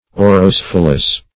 Search Result for " aurocephalous" : The Collaborative International Dictionary of English v.0.48: Aurocephalous \Au`ro*ceph"a*lous\, a. [Aurum + cephalous.]
aurocephalous.mp3